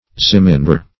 Zemindar \Zem`in*dar"\, n.